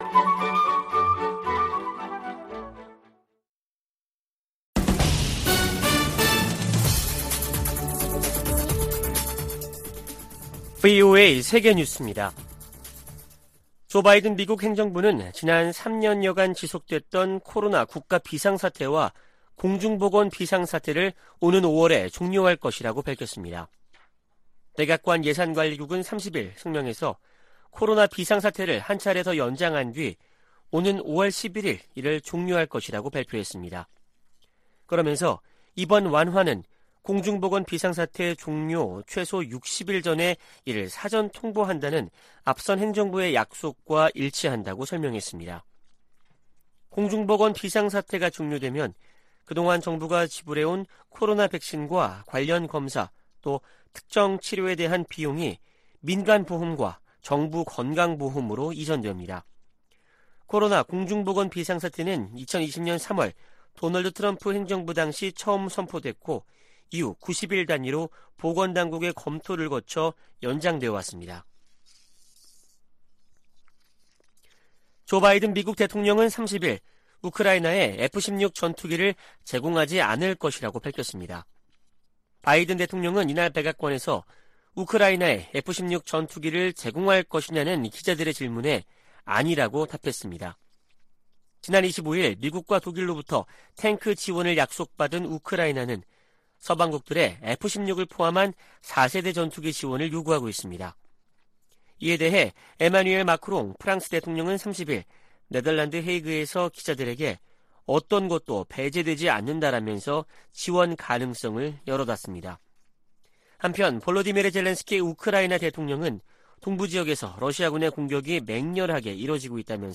VOA 한국어 아침 뉴스 프로그램 '워싱턴 뉴스 광장' 2023년 2월 1일 방송입니다. 미-한 두 나라 국방 장관들이 서울에서 회담을 갖고, 한국에 대한 미국의 확고한 방위공약을 보장하기 위해 미국의 확장억제 실행력 강화 조치들을 공동으로 재확인해 나가기로 했습니다. 북한이 함경남도 마군포 엔진시험장에서 고체연료 엔진 시험을 한 정황이 포착됐습니다.